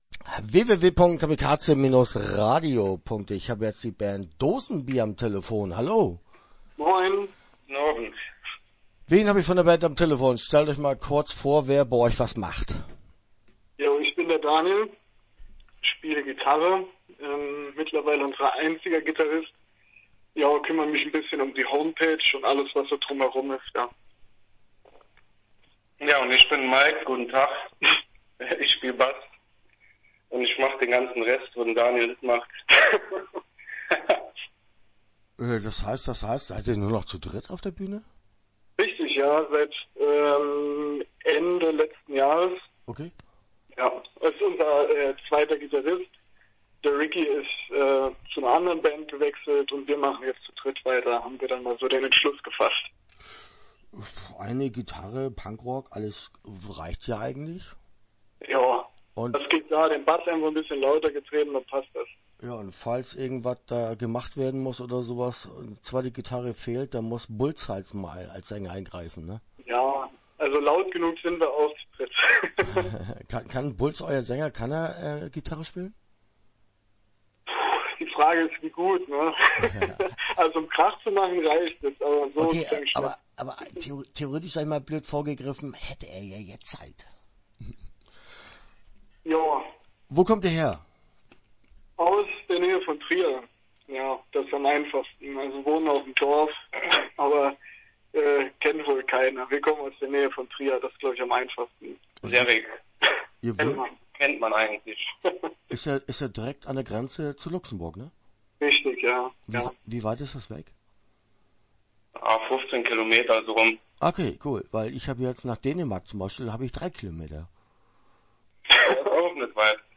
Start » Interviews » Dosenbier